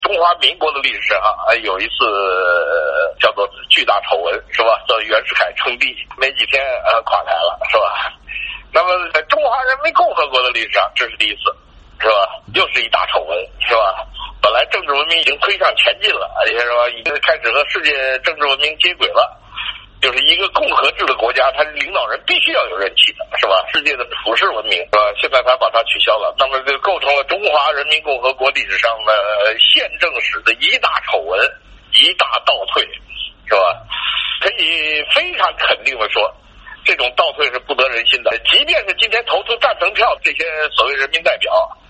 李大同今天晚上接受美国之音电话采访时表示，目前他被当局严密监管，住所楼道外有人日夜看守。